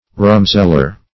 Rumseller \Rum"sell`er\ (r[u^]m"s[e^]l`[~e]r), n.